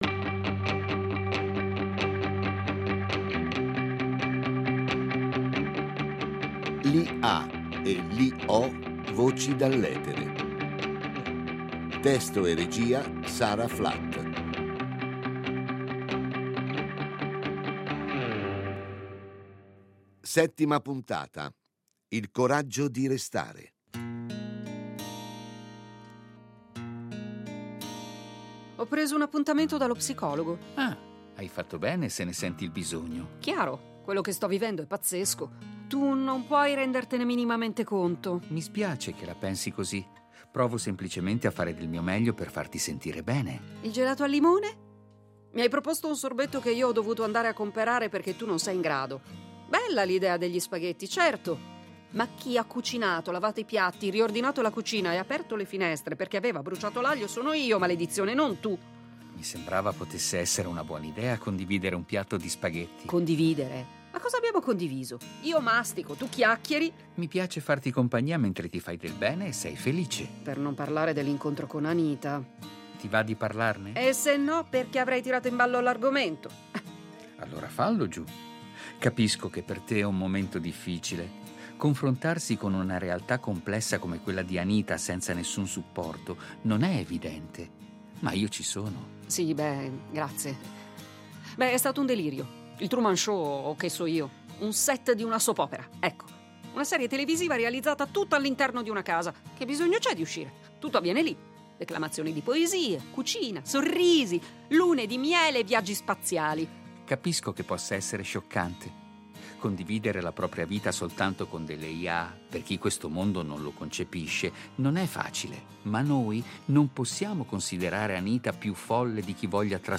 Giulia, una giornalista traghettatrice tra i mondi, interpretata da
Robi, un’IA evoluta, impersonata dalla voce più che reale di